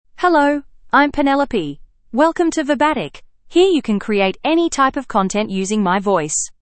Penelope — Female English (Australia) AI Voice | TTS, Voice Cloning & Video | Verbatik AI
Penelope is a female AI voice for English (Australia).
Voice sample
Female
Penelope delivers clear pronunciation with authentic Australia English intonation, making your content sound professionally produced.